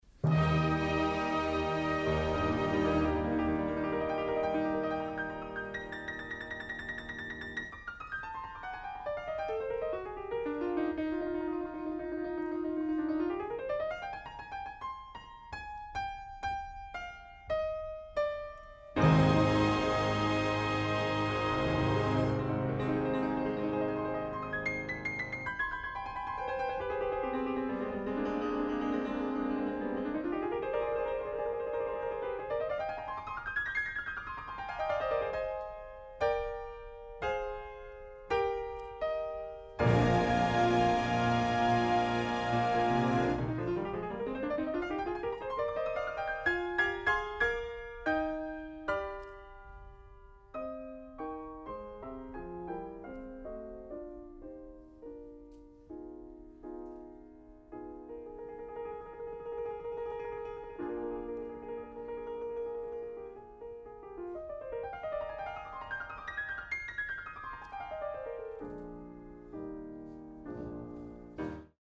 Additional Date(s)Recorded September 29, 1973 in the Tarrant County Convention Centre Theatre, Fort Worth, Texas
Short audio samples from performance